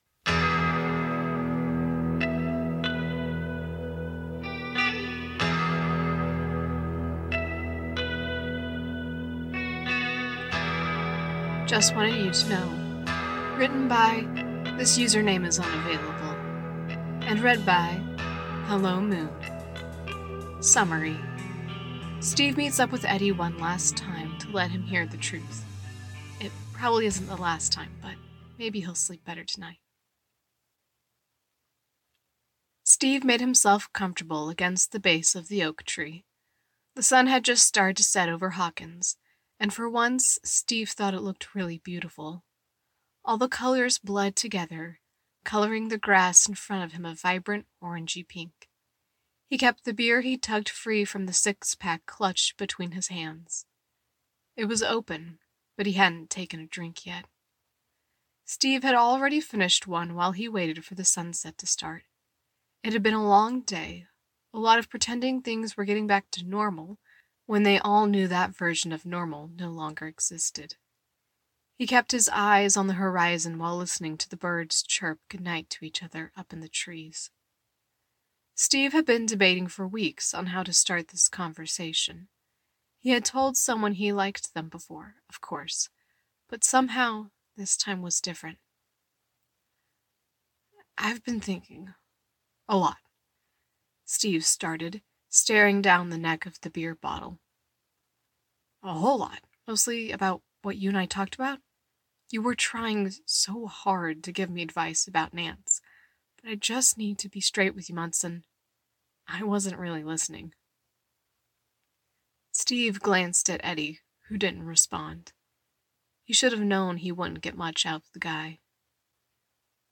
with sfx download mp3: here (r-click or press, and 'save link') [8 MB, 00:10:11]